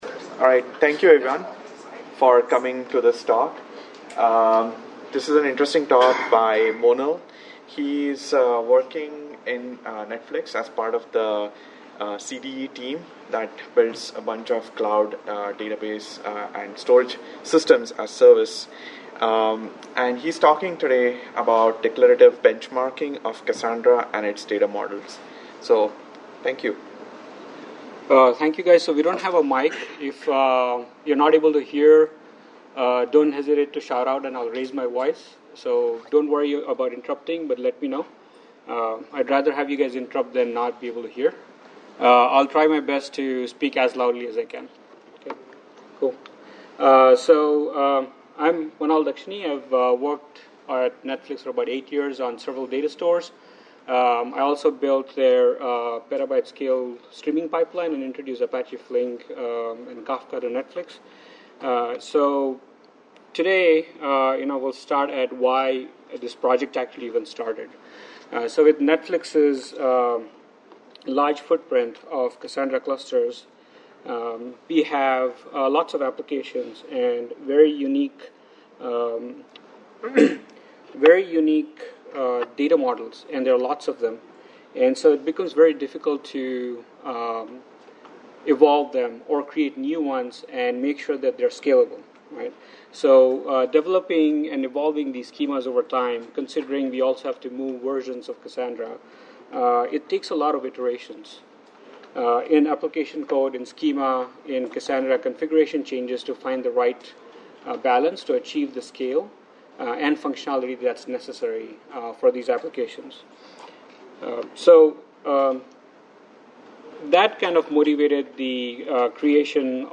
This talk presents: 1.